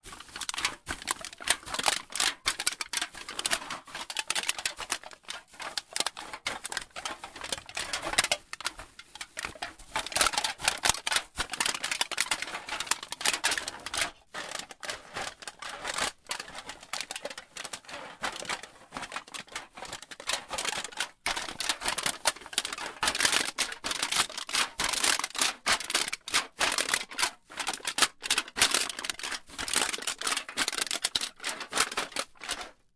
FP铝罐紧缩
描述：铝罐被压在塑料购物袋内。
Tag: 紧缩 粉碎